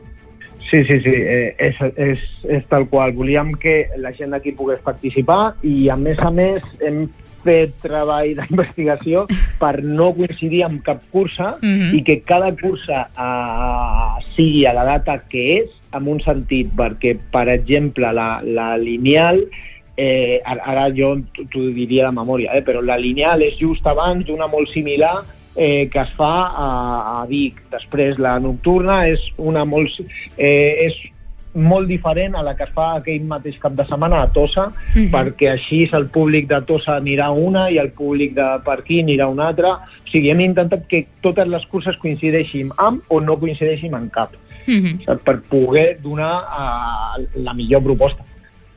Supermatí - entrevistes
I per parlar-ne ens ha visitat al Supermatí el regidor d’esports de l’Ajuntament de Castell d’Aro, Platja d’Aro i s’Agaró, Marc Medina.